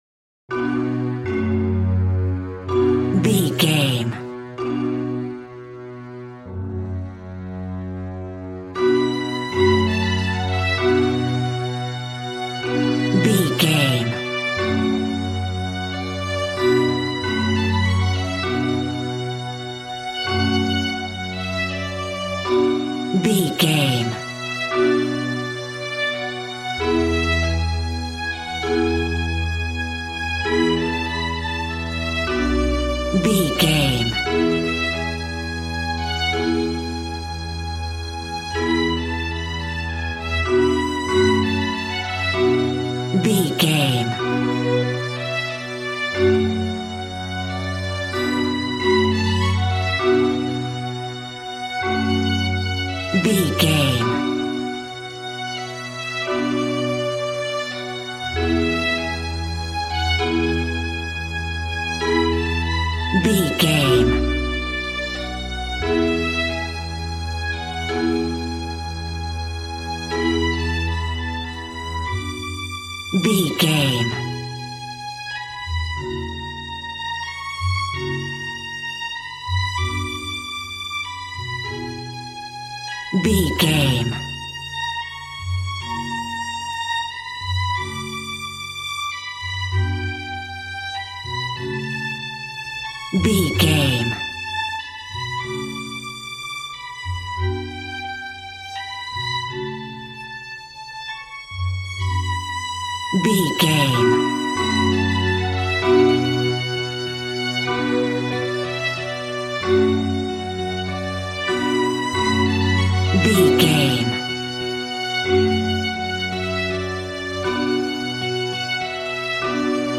Aeolian/Minor
cheerful/happy
joyful
drums
acoustic guitar